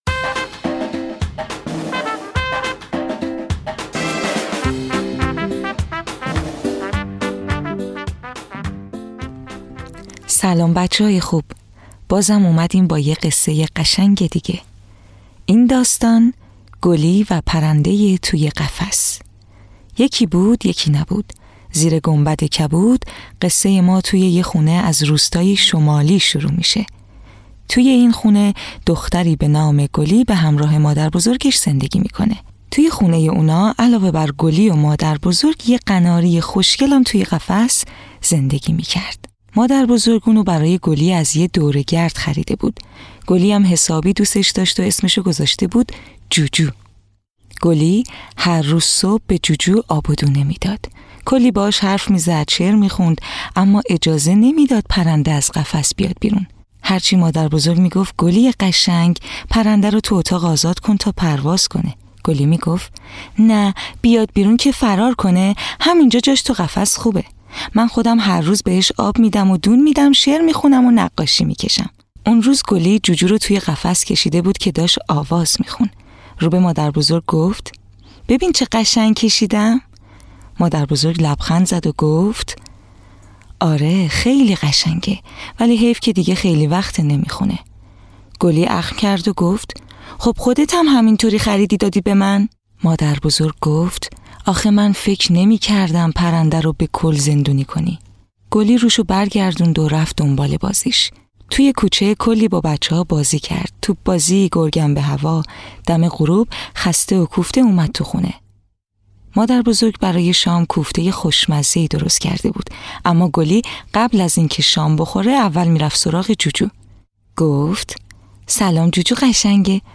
قصه‌های کودکانه صوتی - این داستان: گُلی و پرنده تو قفس
این داستان لطیف، کودکان را با مفاهیم عشق، آزادی و درک احساسات دیگران آشنا می‌کند؛ روایتی آرام و شاعرانه از رهایی و مهربانی.
تهیه شده در استودیو نت به نت